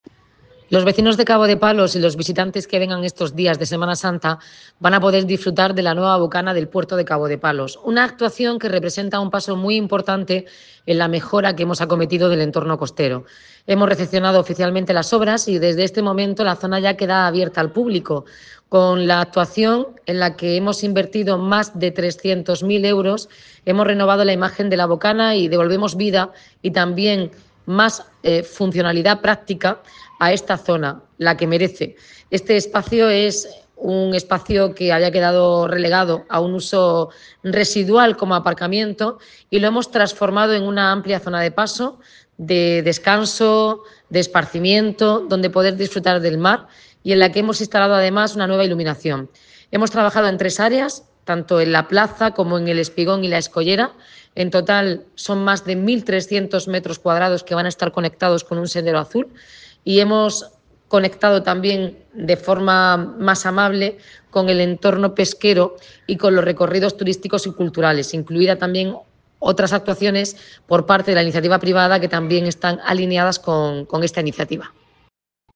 Enlace a Declaraciones de la alcaldesa, Noelia Arroyo, sobre la renovación de la Bocana de Cabo de Palos